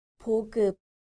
• 보급
• bogeup